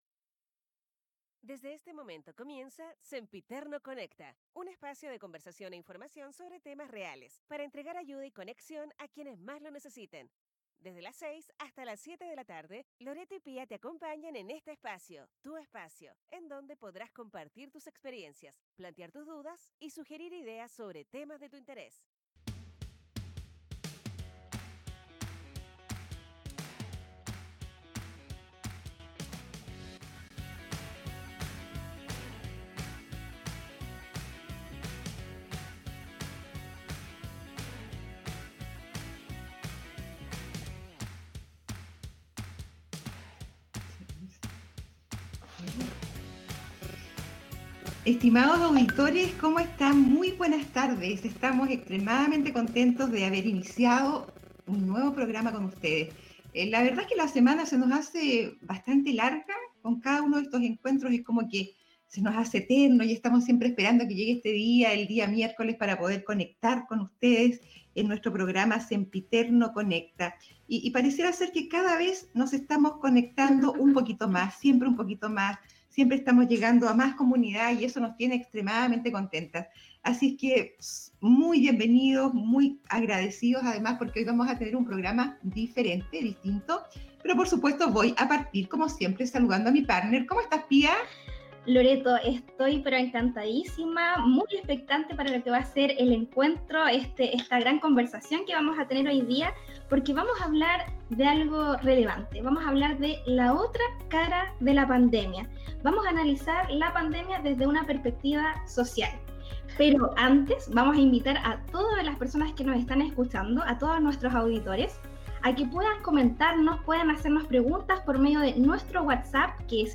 4to capitulo del programa de radio digital: Sempiterno Conecta